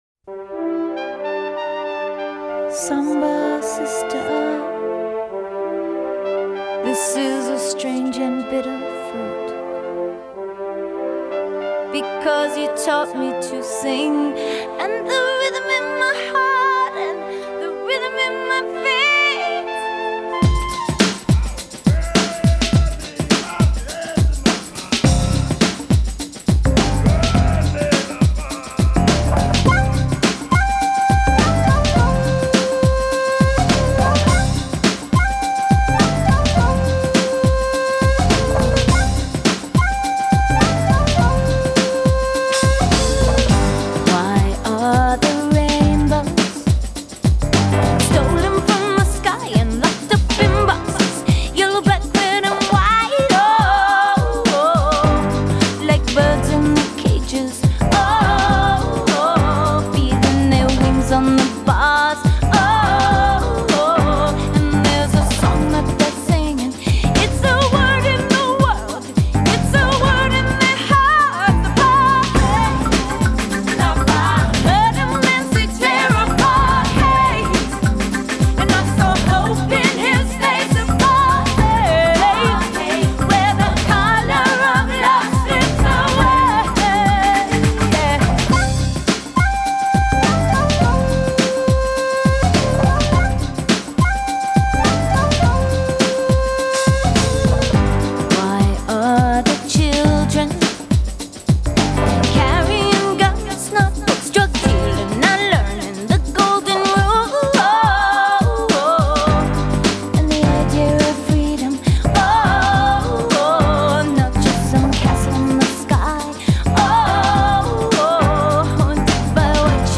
a chorus of children